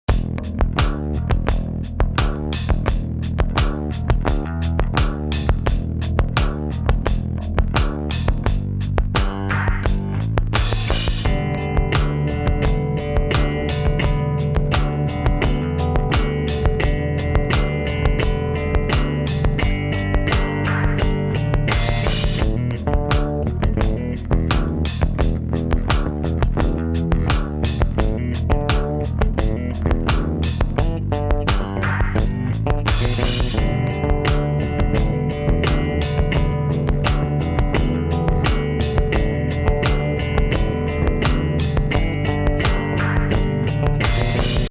home of the daily improvised booty and machines -
bass grooves